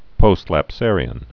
(pōstlăp-sârē-ən)